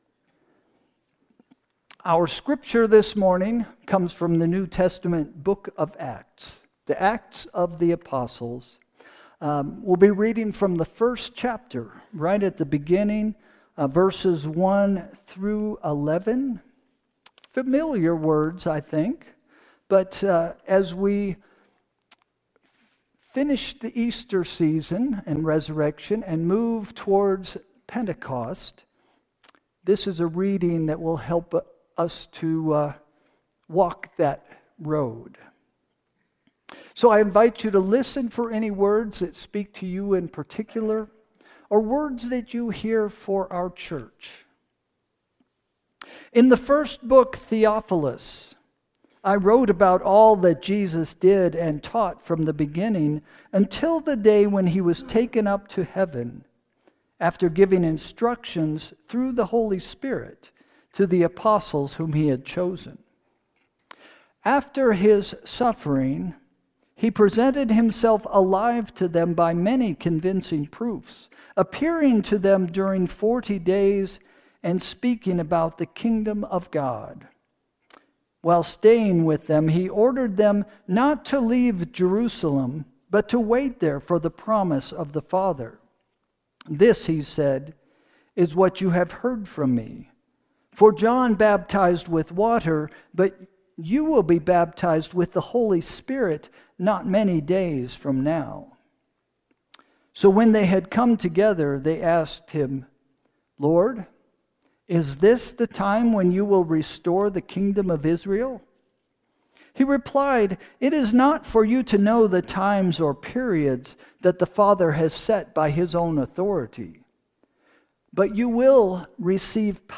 Sermon – June 1, 2025 – “Are We Almost There?”